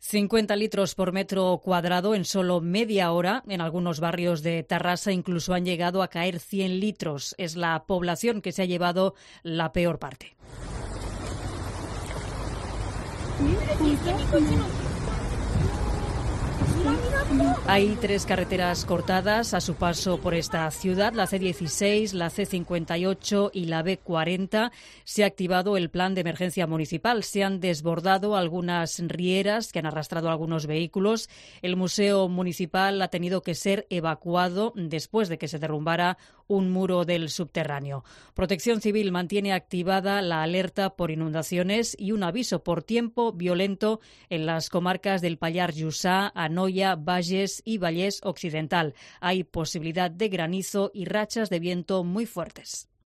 Auténticas riadas en las calles de Terrassa, en Barcelona, por las fuertes lluvias y granizo